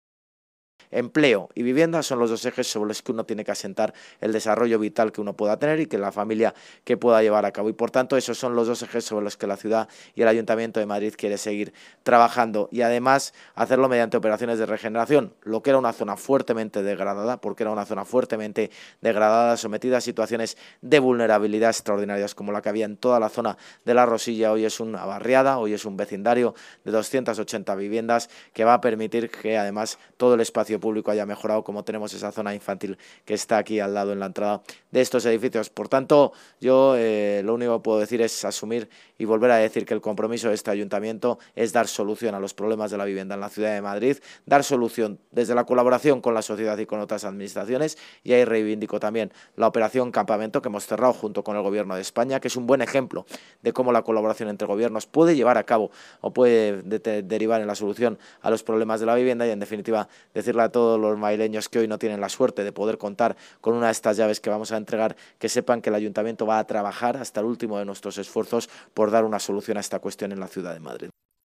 Nueva ventana:Intervención del alcalde de Madrid, José Luis Martínez-Almeida, en su visita de hoy